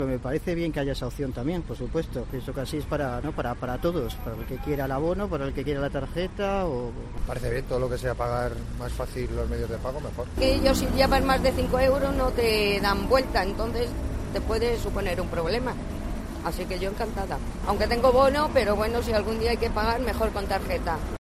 En COPE nos hemos acercado hasta las marquesinas de autobuses de la Plaza de Cibeles para ver cómo se han tomado los usuarios esta nueva facilidad de pago.